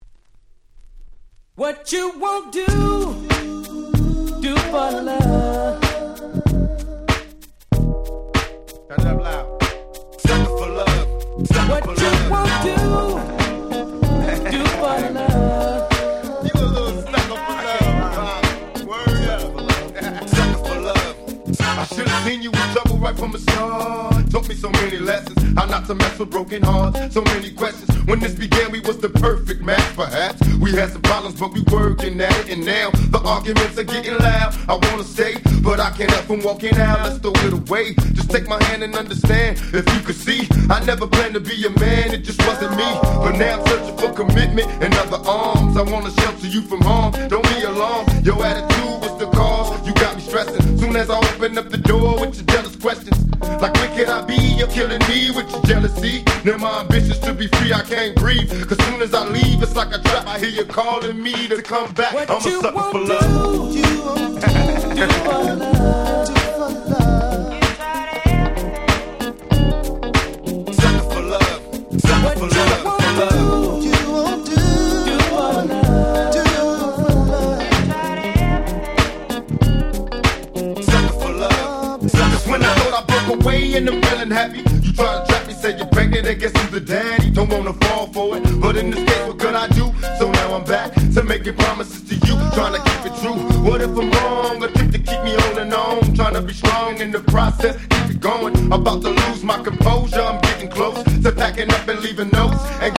West Coast Hip Hop Classic !!